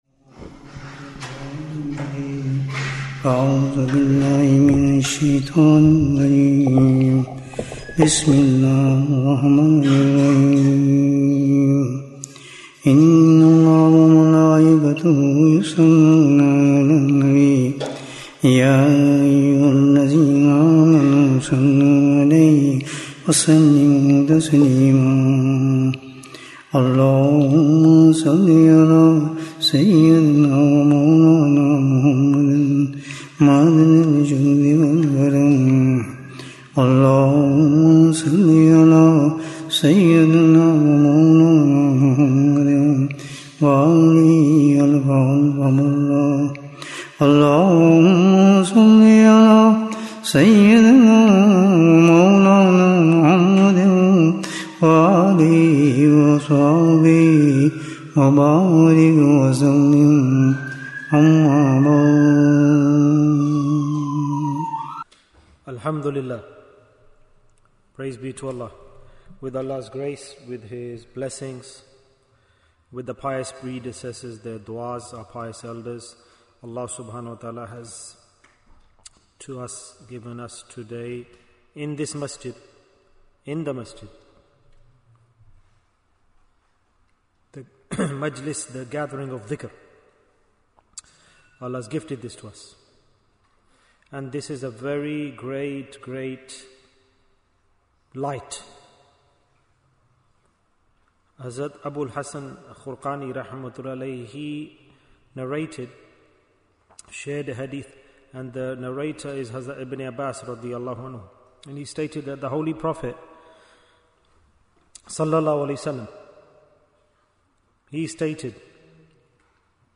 Who Will be Called to Jannah First? Bayan, 28 minutes19th June, 2025